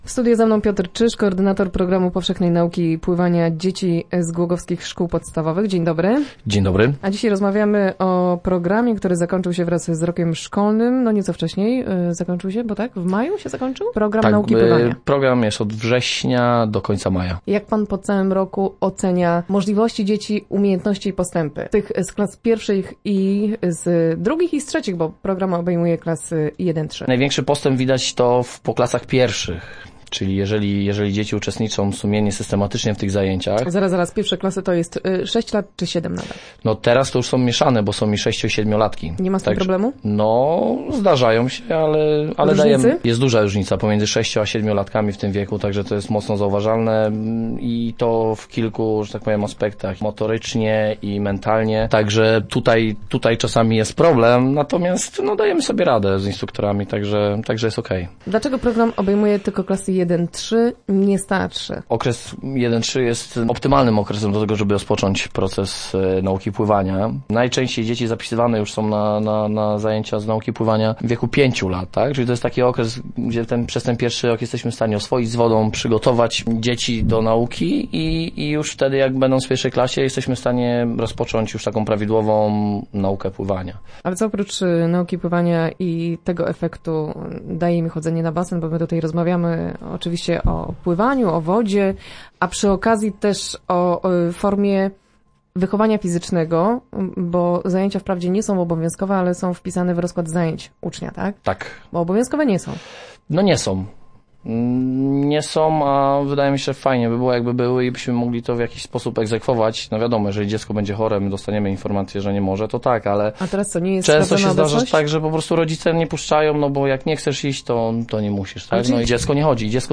0626rozmowa.mp3